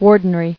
[war·den·ry]